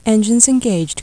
Voices
EnginesEngaged.WAV